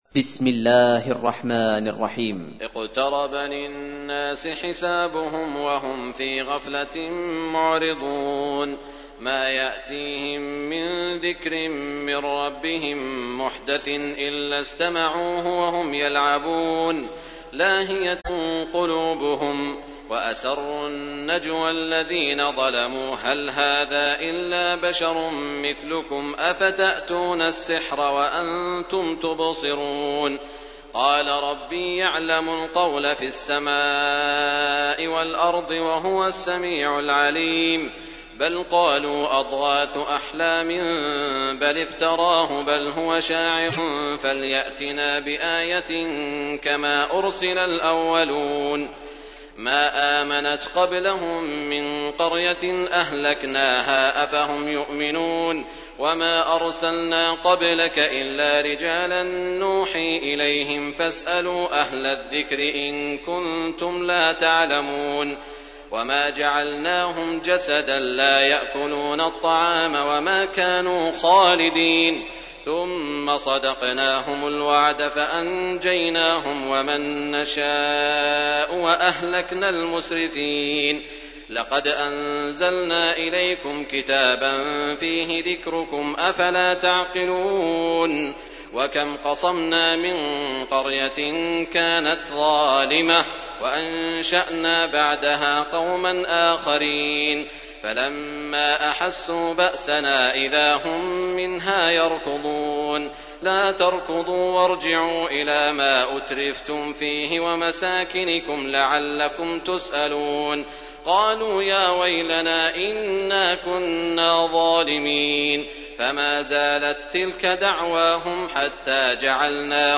تحميل : 21. سورة الأنبياء / القارئ سعود الشريم / القرآن الكريم / موقع يا حسين